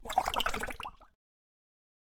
AudioClip_PotionDrop.wav